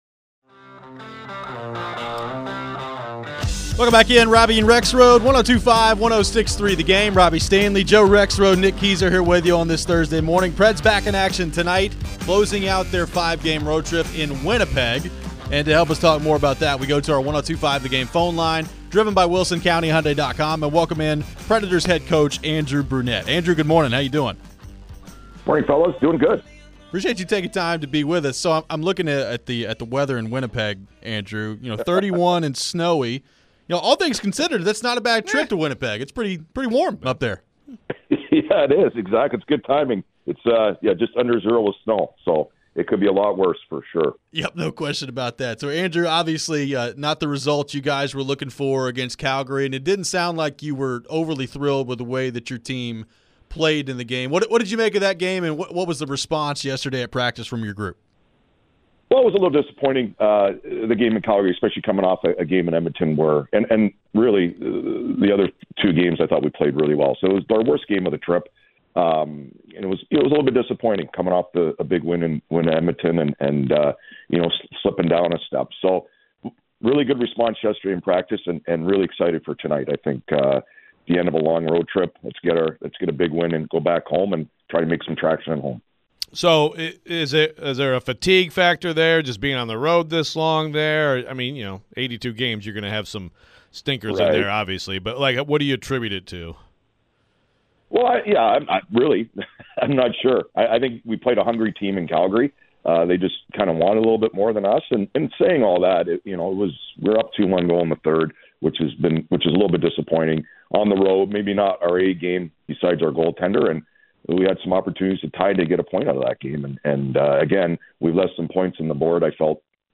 Andrew Brunette Interview (11-9-23)